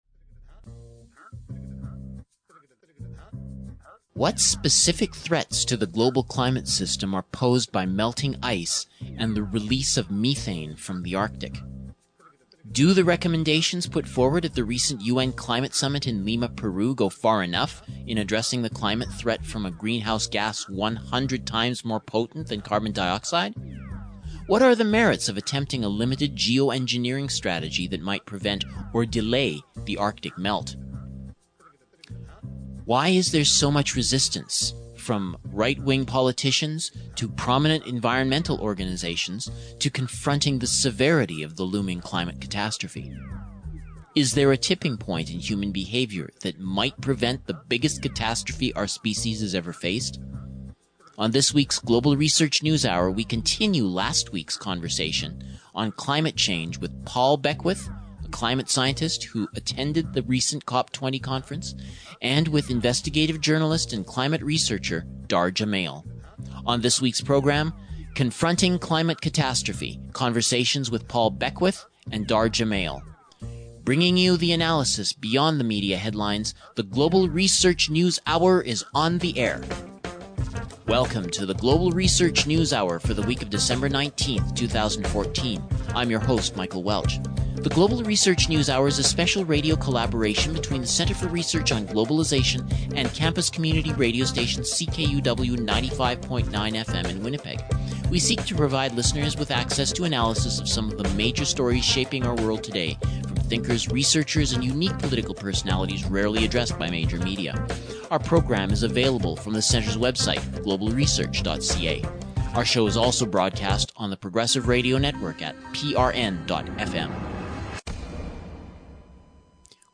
The Methane threat to Global Climate examined by two climate researchers
Stereo